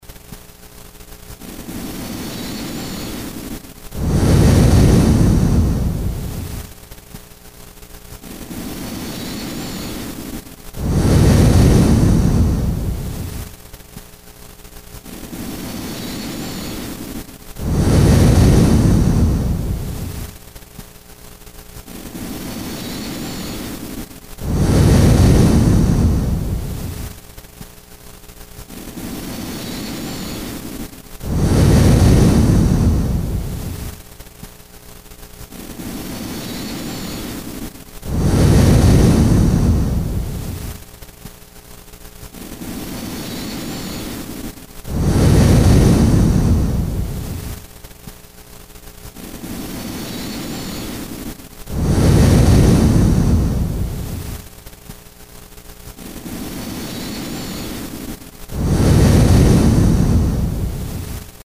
+ exhale count of three
+ pause relaxed count of two
+ inhale count of two or three
These are slow counts, I use my heart beat which is about 60 BPM to make the counts so the counts are about one second each.
Here's an mp3 of about what this breathing pattern sounds like:
paralysis-breathing.mp3